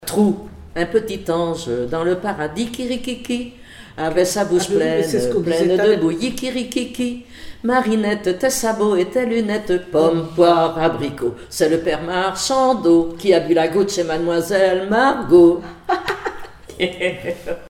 L'enfance - Enfantines - rondes et jeux
formulette enfantine : amusette
Comptines et formulettes enfantines
Pièce musicale inédite